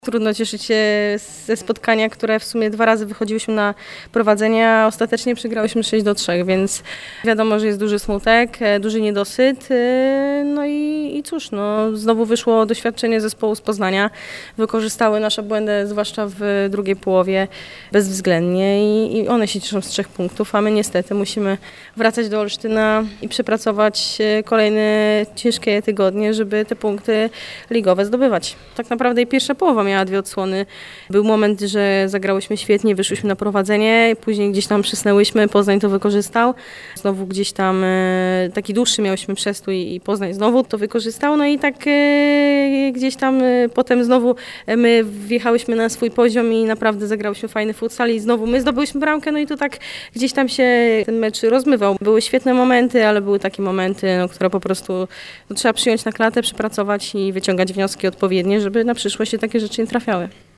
w rozmowie z reporterem poznańskiego Radia Meteor.